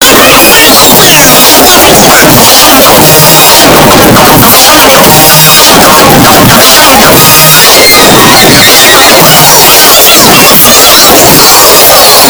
Nick Eh 30 Intro High Pitched Sound Effect Download: Instant Soundboard Button
Nick Eh 30 Intro High Pitched Sound Button - Free Download & Play